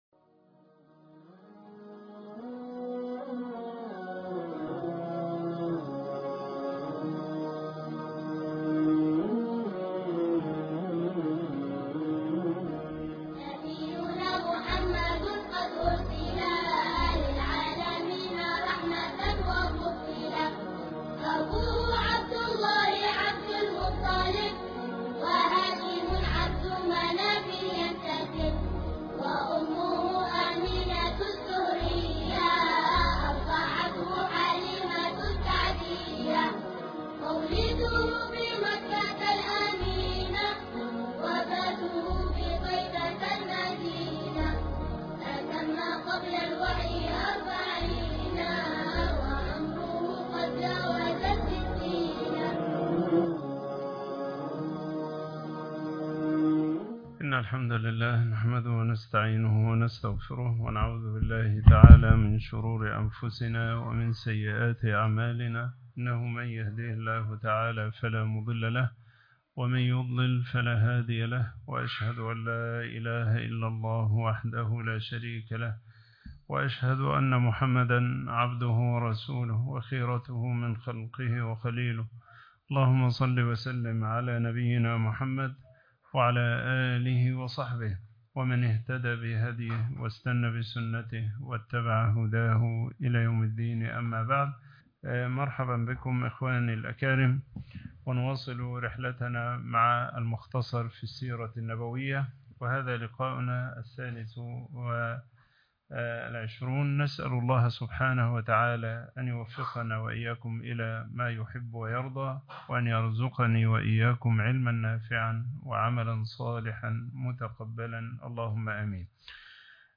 المختصر فى السيرة النبوية على صاحبها أفضل الصلاة وأتم التسليم الدرس الثالث و العشرون